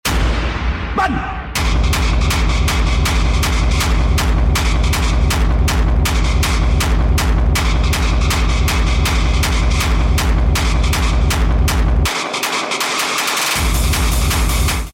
RAVE VIBES 🚀 | HARD TECHNO EDIT